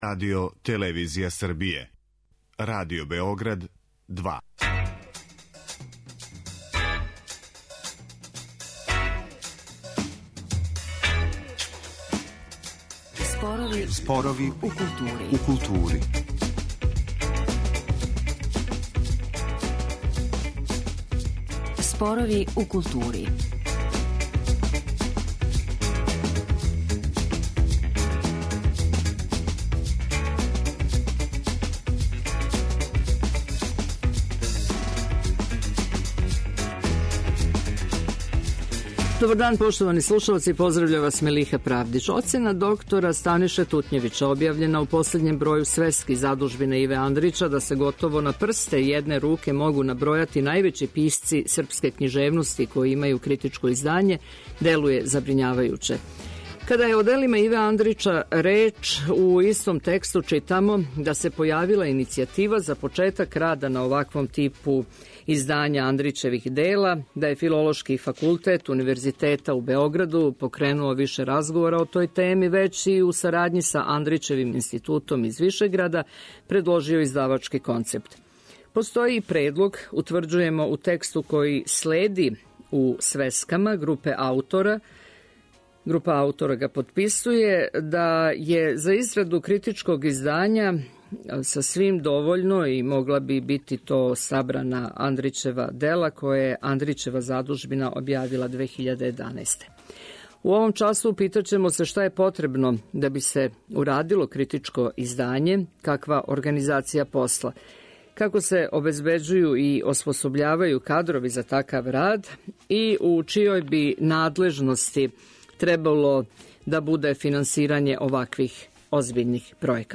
У данашњем разговору ћемо се ипак задржати на Андрићевом делу јер је готово несхватљиво да је једини наш нобеловац без критичког издања свог дела.